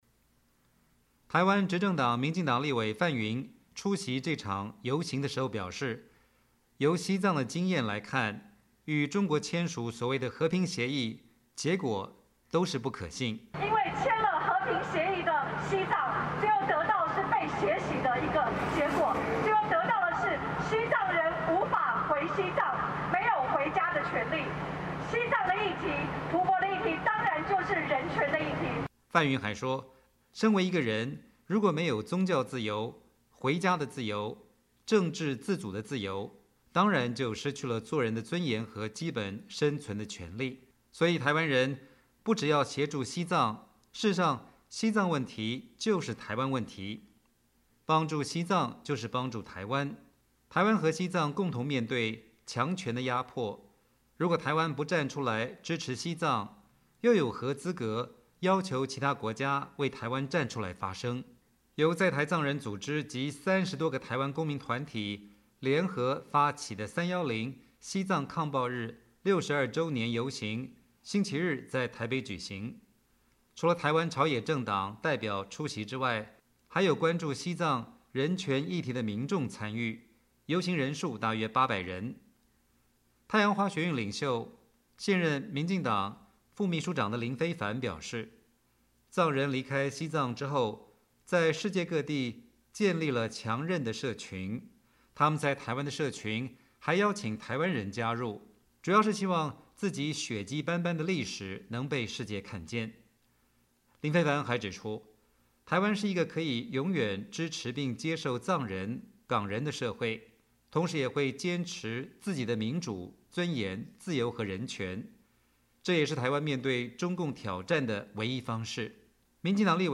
西藏抗暴62周年游行3月7日在台北举行，出席的台湾政治人物表示，西藏问题就是台湾问题，台湾应该记取西藏与中国签署和平协议的教训，台藏共同面对强权的压迫。